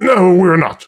woodboxdestroyed06.ogg